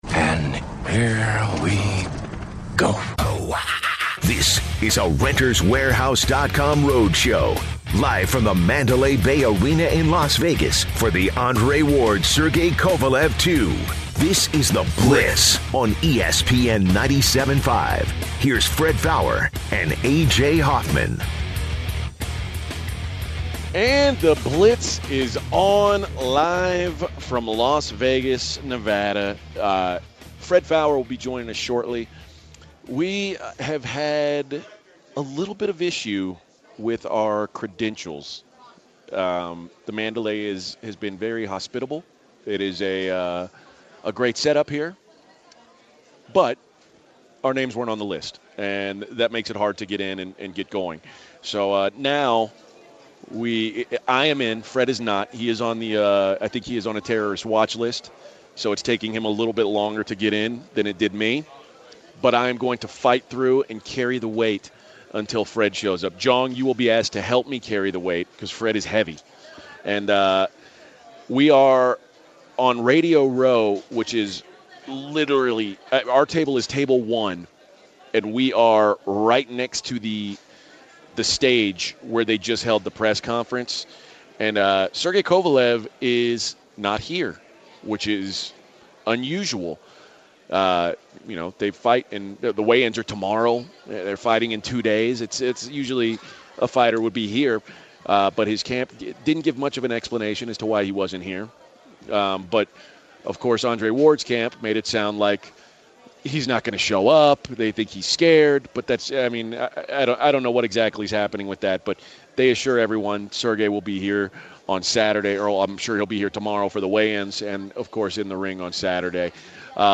In the first hour the guys are coming to us live from Las Vegas for the Andre Ward/Sergey Kovalev fight.